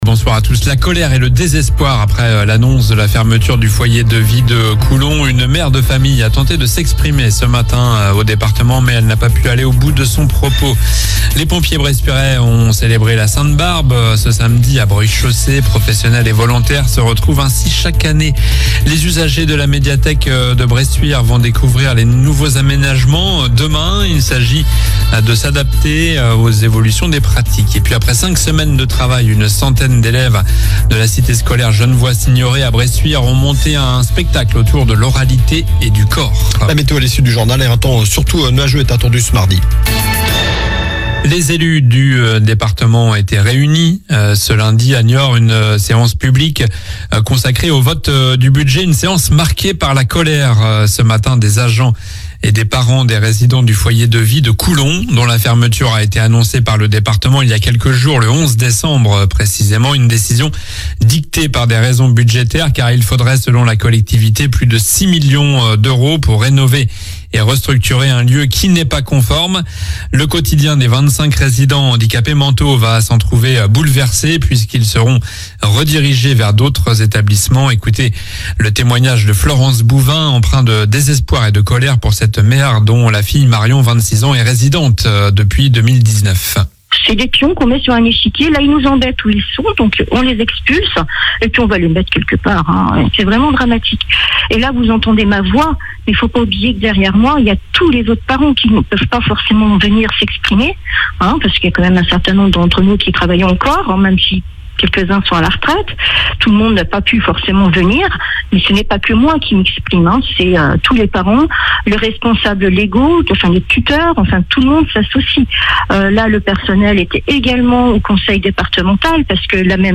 Journal du lundi 16 décembre (soir)